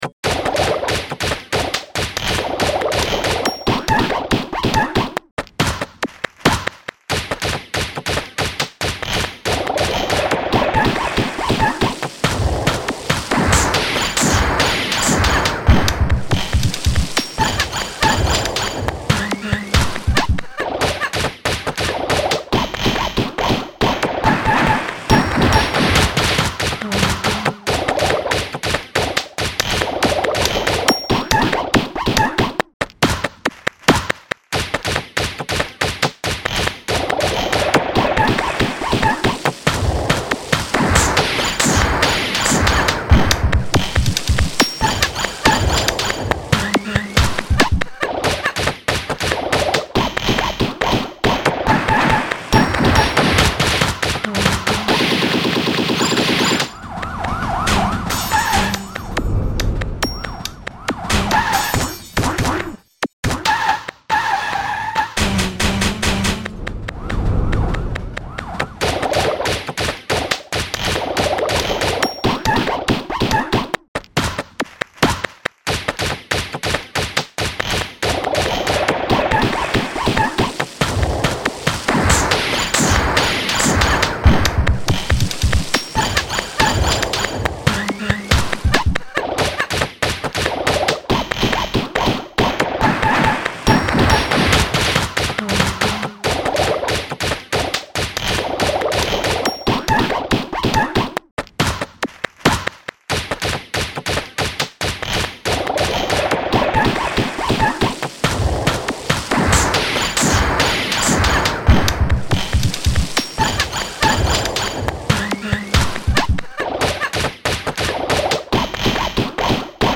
Made with ENCORE drum effects
PERCUSSION MUSIC